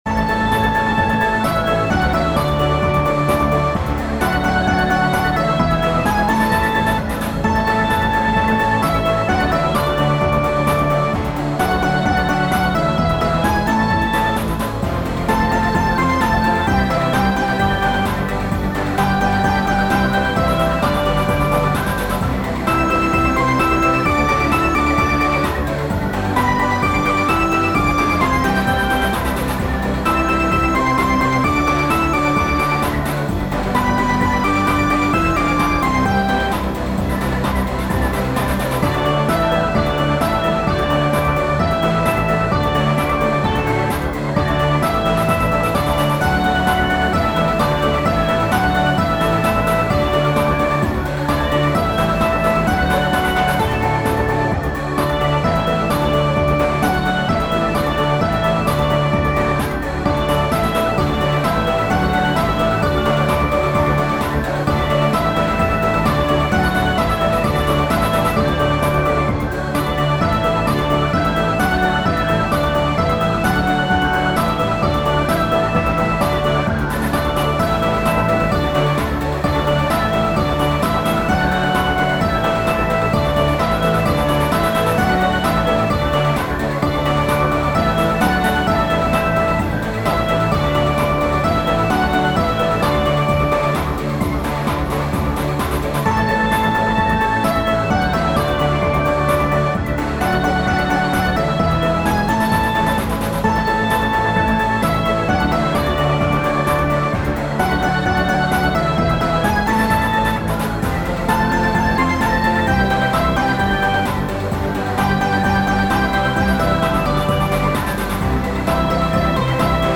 midi-demo 3